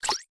menu-back-click.ogg